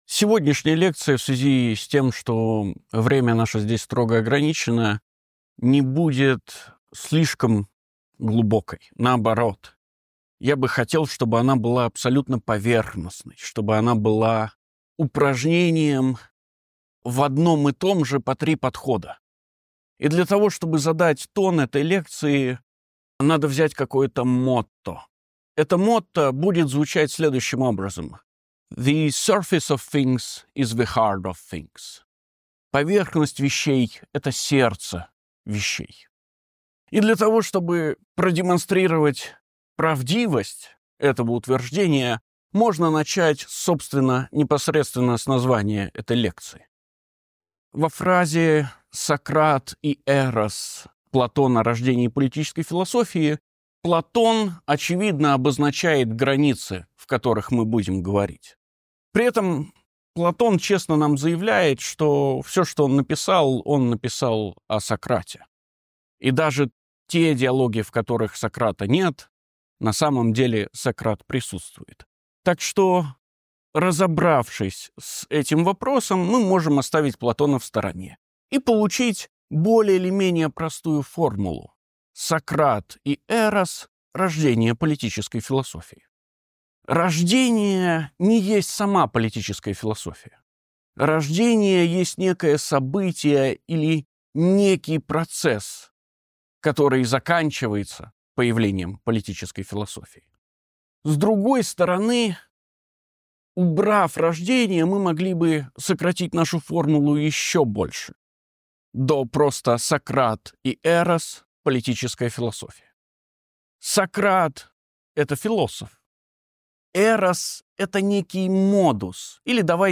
Данная лекция прошла в 2024 году в Лектории парка Горького при поддержке Института философии РАН.